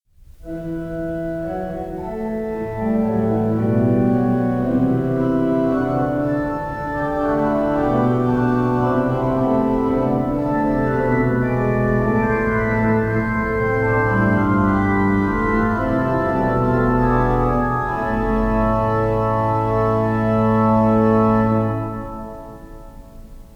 Stereo recording made 10-11 March 1960
in St John’s College Chapel, Cambridge